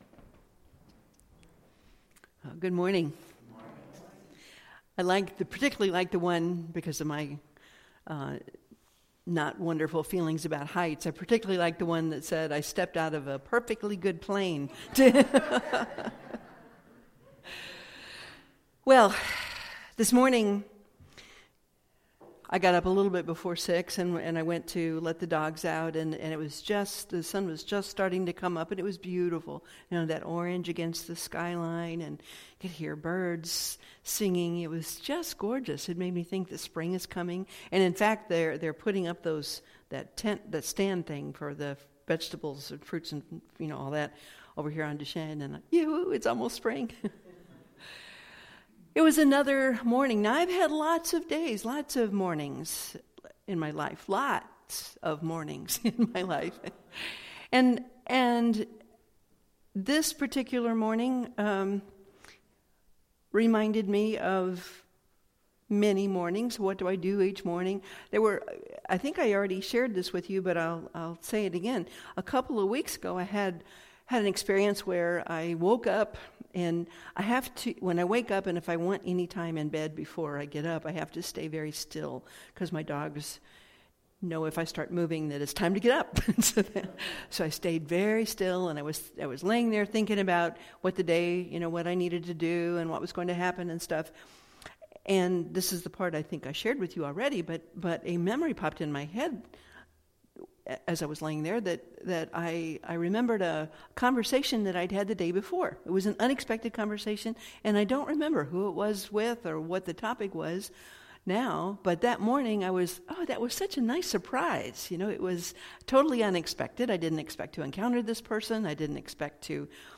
Series: Sermons 2016